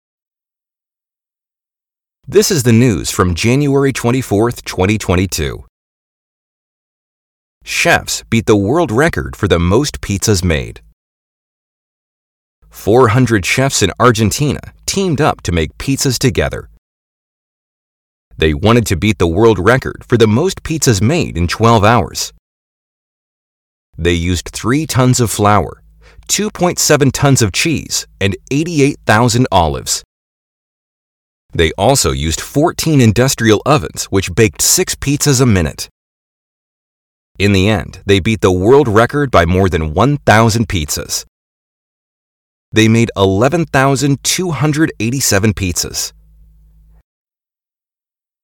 Lesson 16 - Shadowing
native speakers